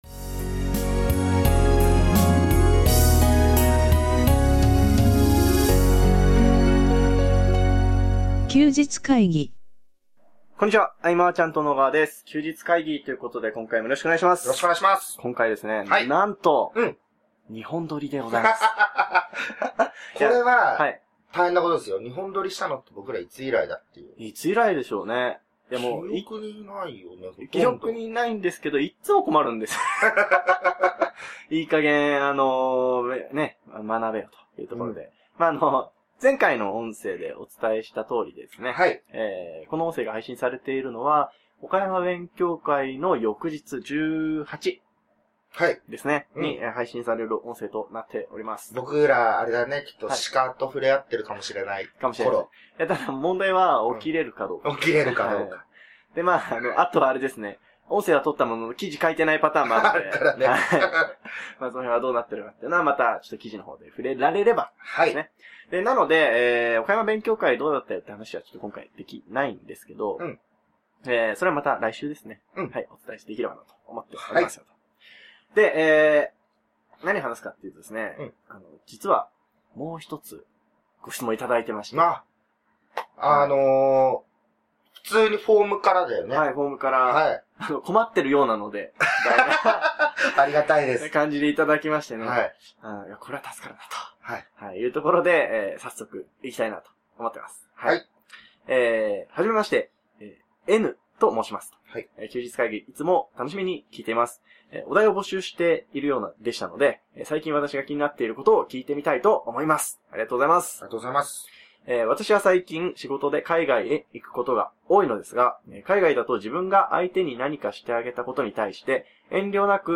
笑 先週末は第32回マーチャントクラブ岡山勉強会のため、事前収録した音声をお届けします。